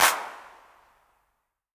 808CP_Tape_ST.wav